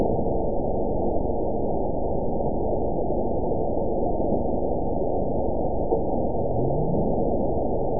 event 922549 date 01/29/25 time 08:13:50 GMT (10 months ago) score 9.40 location TSS-AB04 detected by nrw target species NRW annotations +NRW Spectrogram: Frequency (kHz) vs. Time (s) audio not available .wav